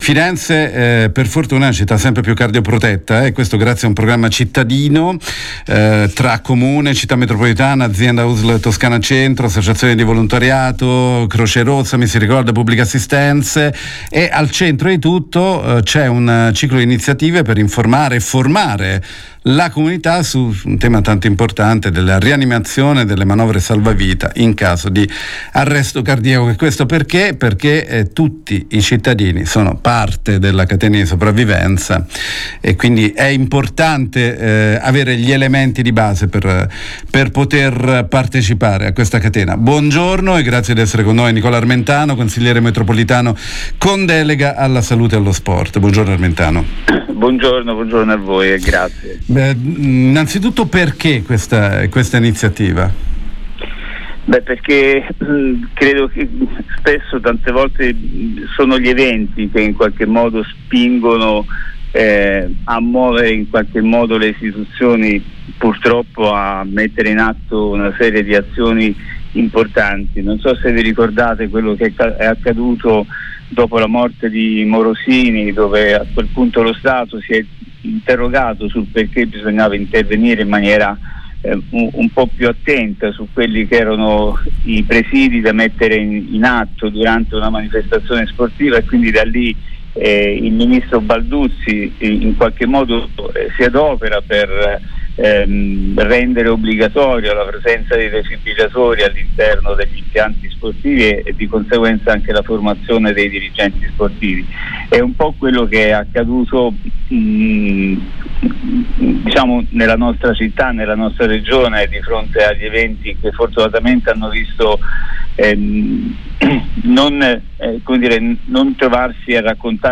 Fulcro del piano sarà un ciclo di iniziative per informare e formare la comunità sul tema tanto importanti della rianimazione e delle manovre salvavita in caso di arresto cardiaco. Ne abbiamo parlato con Nicola Armentano, consigliere metropolitano con deleghe alla Promozione della Salute, Sport e Sociale